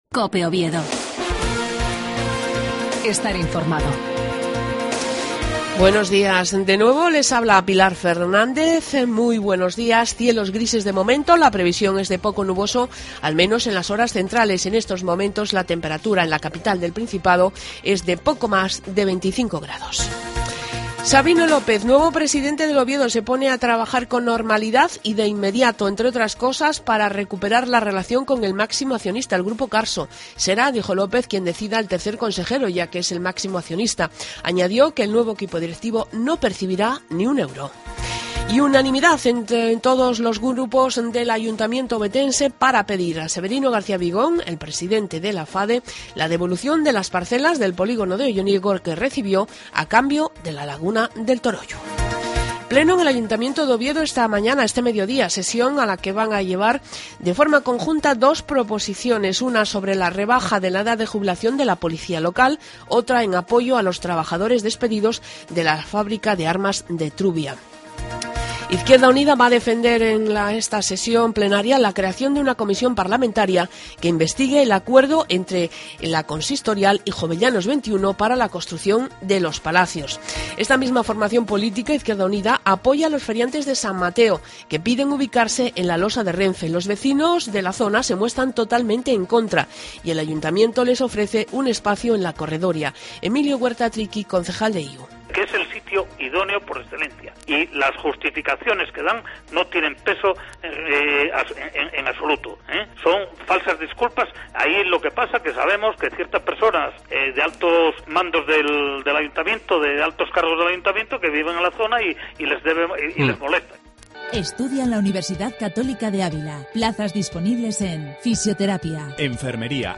AUDIO: LAS NOTICIAS DE OVIEDO A PRIMERA HORA DE LA MAÑANA.